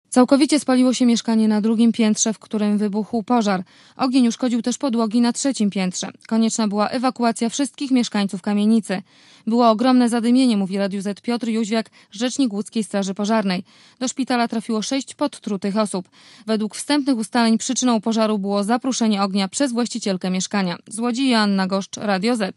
Relacja reportera Radia Zet (180Kb)